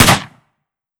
12ga Pump Shotgun - Gunshot B 002.wav